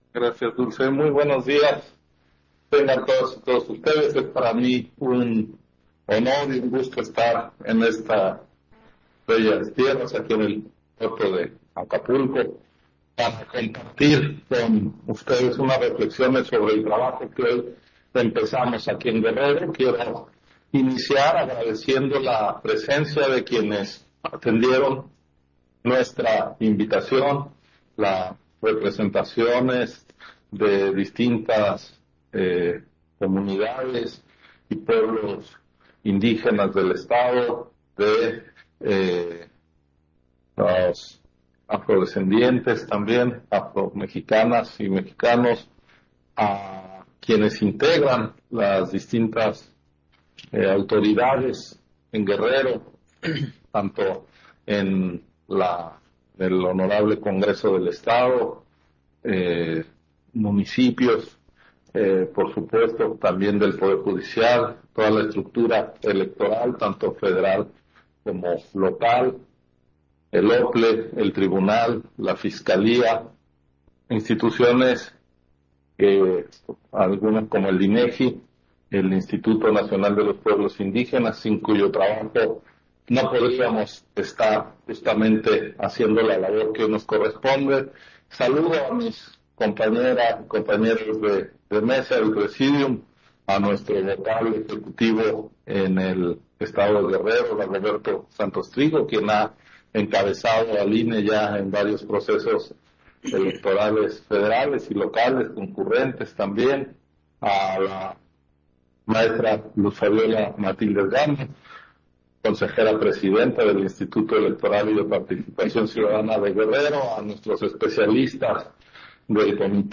250422_AUDIO_INTERVENCIÓN-CONSEJERO-MURAYAMA-FORO-ESTATAL-DE-DISTRITACIÓN-NACIONAL-ELECTORAL-2021-2023 - Central Electoral